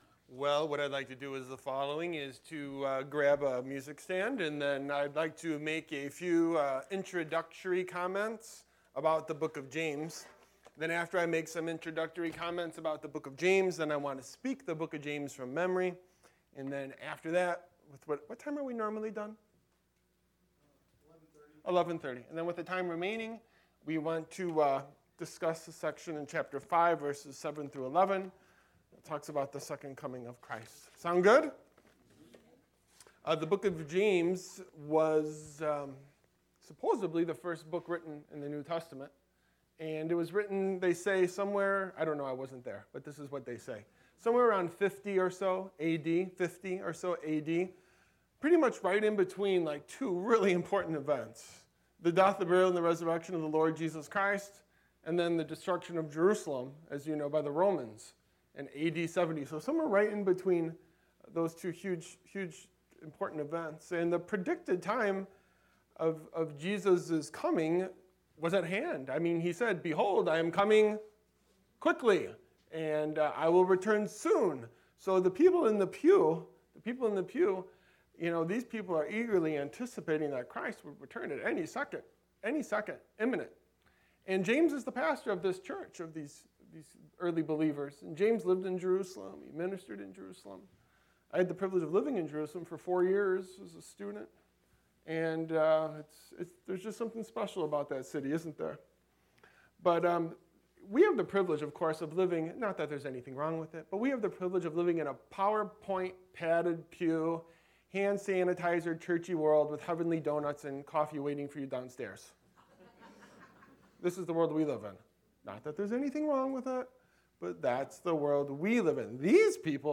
Special Sermon